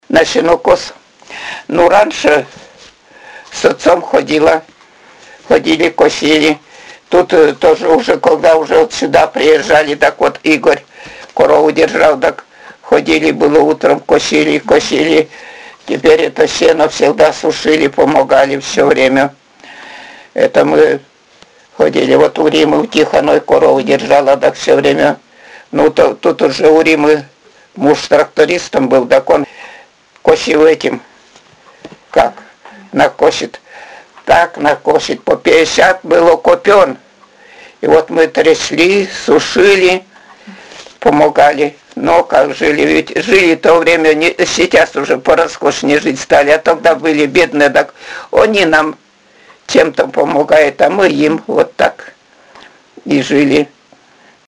«На сенокос? Ну раньше с отцом ходила…» — Говор северной деревни
Пол информанта: Жен.
Аудио- или видеозапись беседы: